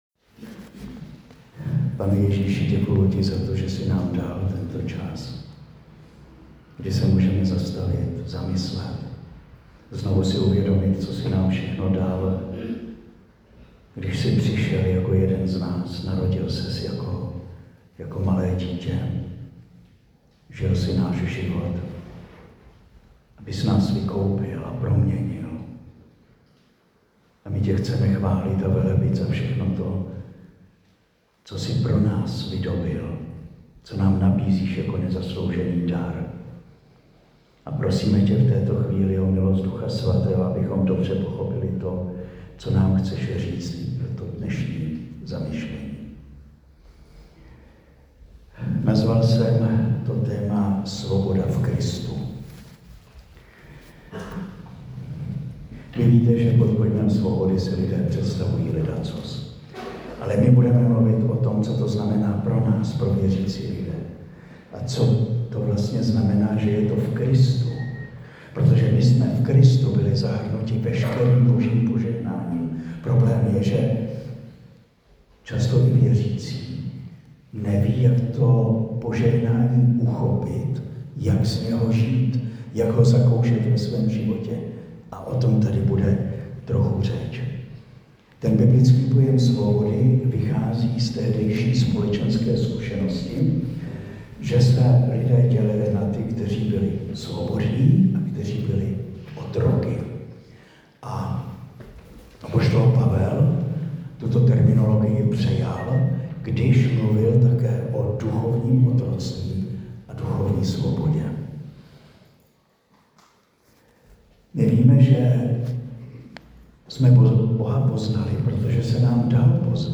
Záznam přednášky ze 4. ledna 2025 (Dačice).